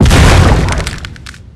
Index of /cstrike_backup/sound/knifes/hammer
hit_stab.wav